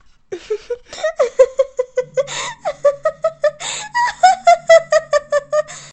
girl insane laugh
Category 🗣 Voices
crazy female girl insane laugh laughter voice woman sound effect free sound royalty free Voices